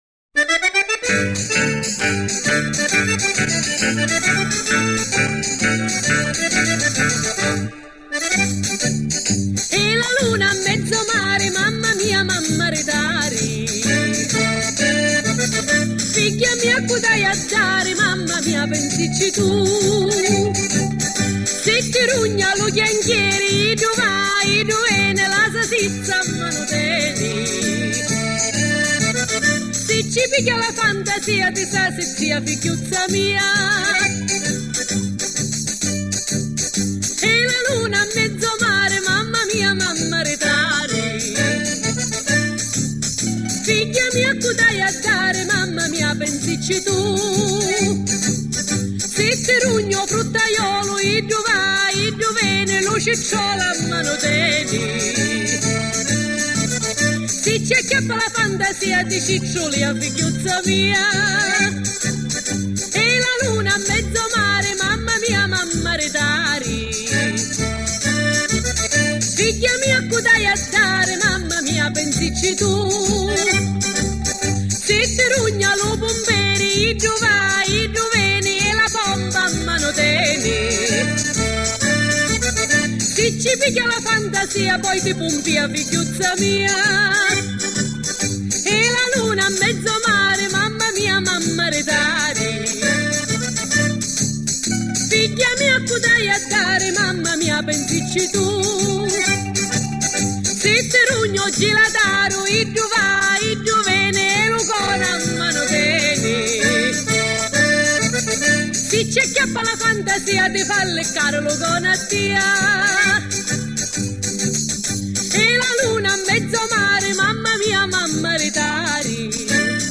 - Versione messinese -